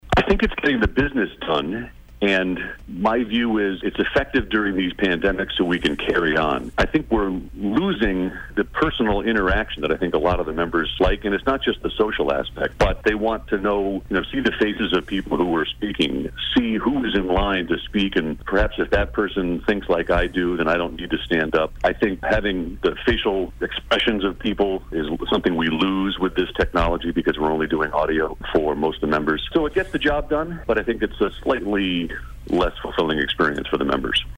When it comes to the remaining articles Hiss says one in particular will likely generate a great deal of discussion.